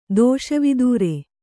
♪ dōṣa vidūre